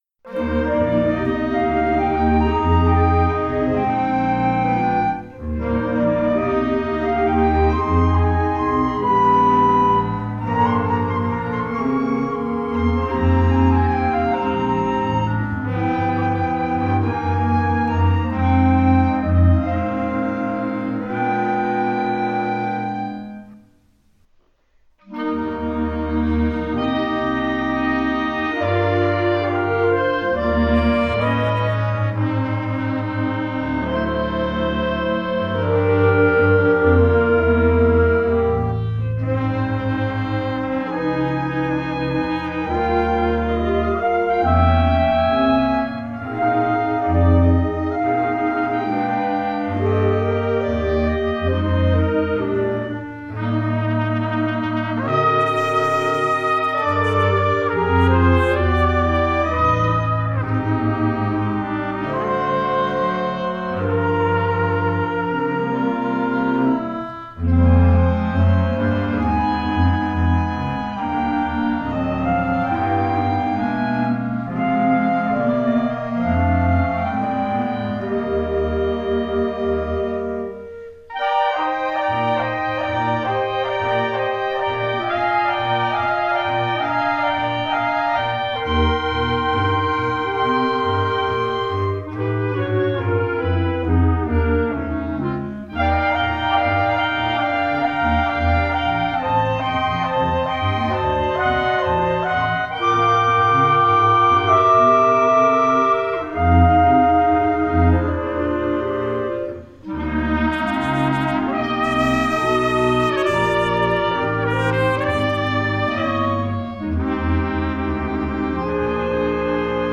Below is the result of all the eligible submitted recording mixed together…